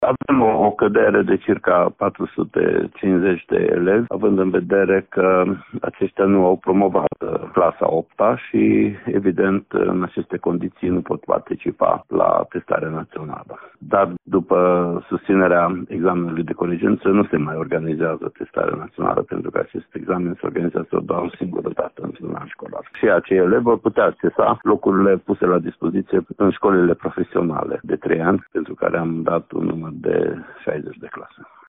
Inspectorul şcolar general al județului Mureș, Ştefan Someşan.